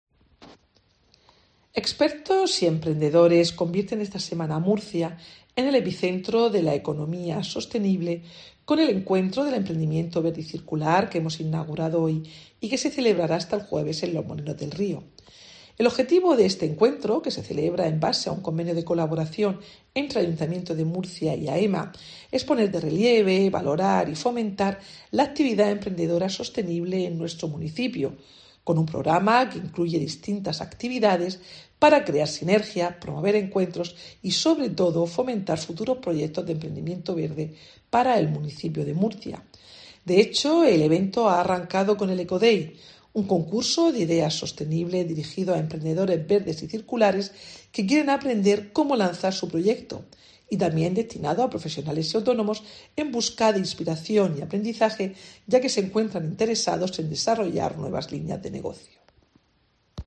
Mercedes Bernabé, concejala de Gobierno Abierto, Promoción Económica y Empleo